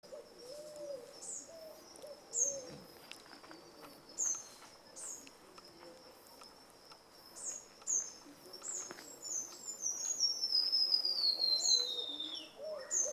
Arañero Silbón (Myiothlypis leucoblephara)
Nombre en inglés: White-rimmed Warbler
Localidad o área protegida: Parque Nacional El Palmar
Condición: Silvestre
Certeza: Fotografiada, Vocalización Grabada
aranero-silbon-palmar.mp3